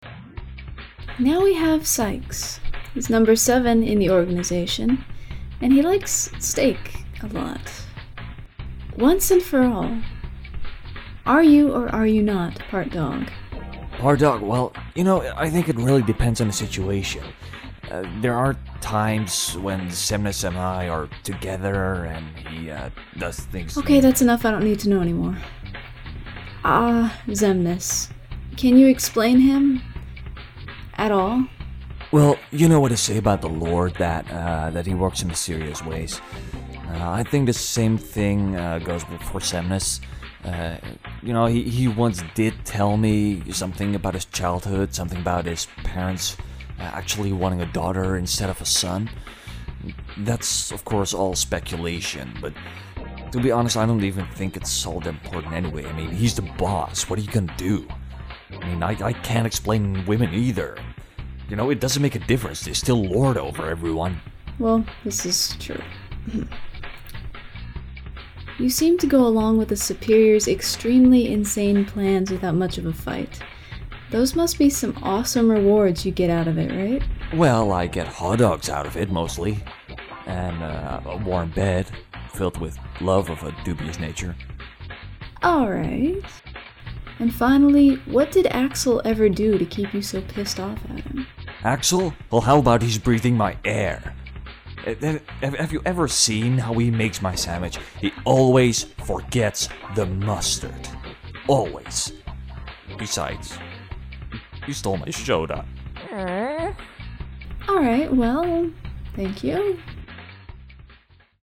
Character Interviews
07SaixInterview.mp3